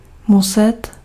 Ääntäminen
Synonyymit mít Ääntäminen : IPA: [mʊsɛt] Haettu sana löytyi näillä lähdekielillä: tšekki Käännöksiä ei löytynyt valitulle kohdekielelle.